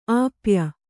♪ āpya